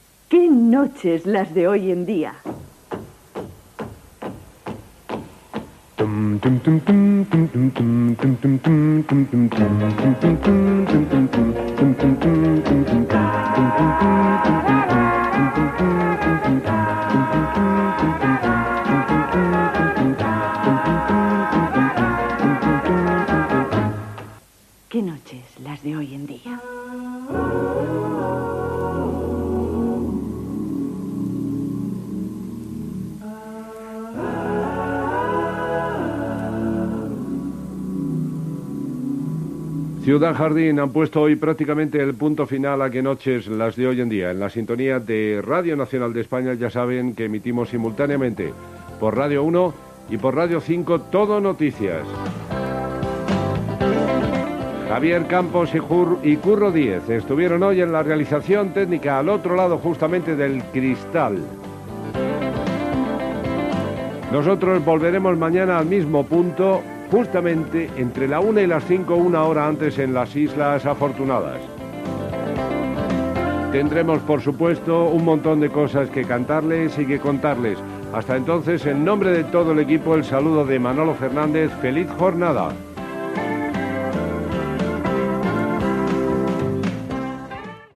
Indicatiu del programa, emissores de RNE que l'emeten i comiat.
Entreteniment